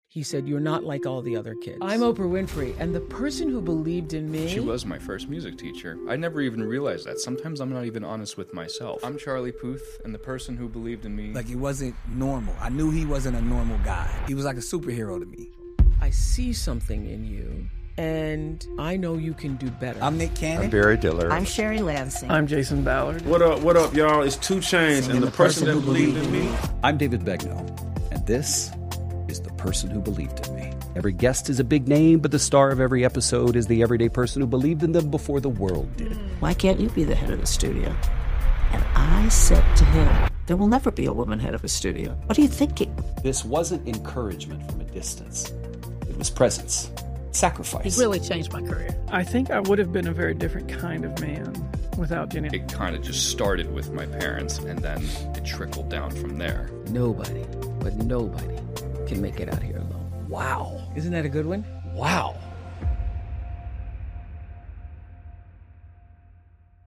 The Person Who Believed In Me is a heartfelt podcast that celebrates the unsung heroes behind some of the world's biggest names. Hosted by Emmy Award-winning journalist David Begnaud, this series shines a spotlight on the everyday people who saw potential, offered unwavering support, and changed the trajectory of extraordinary lives.